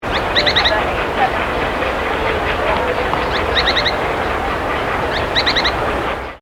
Pijuí Plomizo (Synallaxis spixi)
Nombre en inglés: Spix´s Spinetail
Fase de la vida: Adulto
Localidad o área protegida: Parque Natural Municipal Ribera Norte (San Isidro)
Condición: Silvestre
Certeza: Observada, Vocalización Grabada